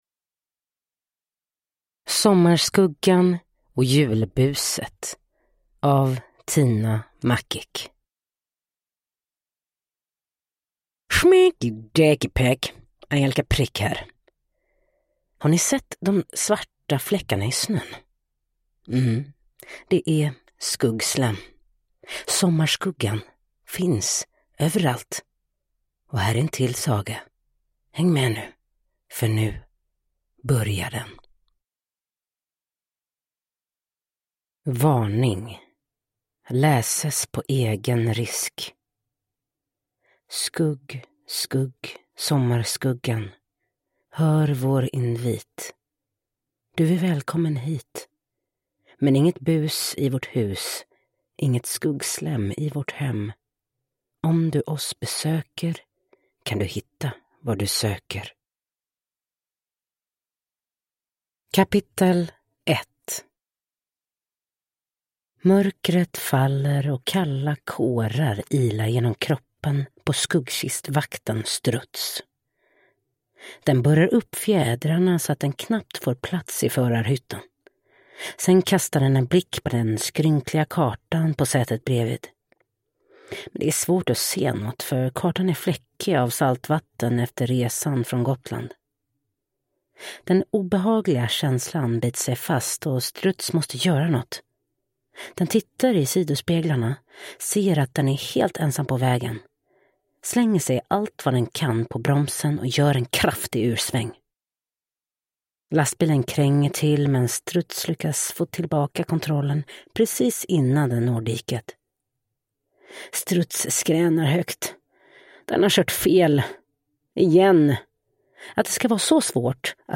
Sommarskuggan och julbuset – Ljudbok